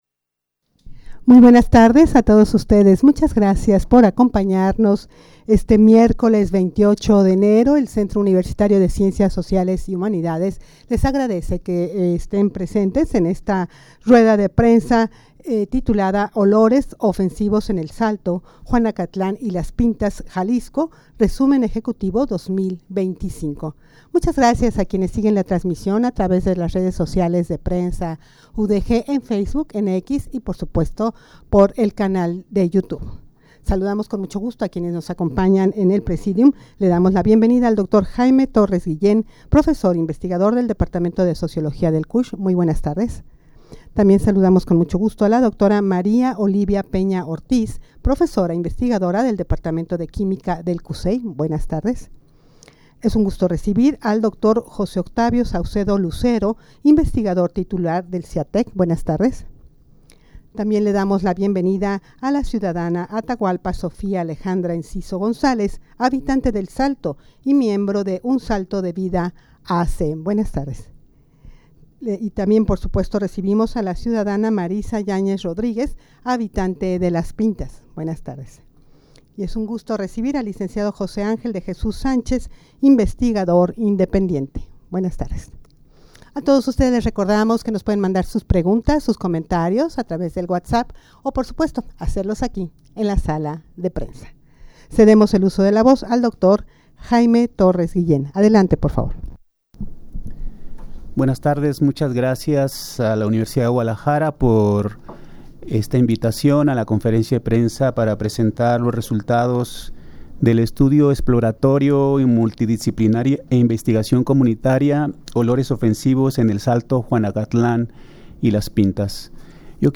Audio de la Rueda de Prensa
rueda-de-prensa-olores-ofensivos-en-el-salto-juanacatlan-y-las-pintas-jalisco-resumen-ejecutivo-2025.mp3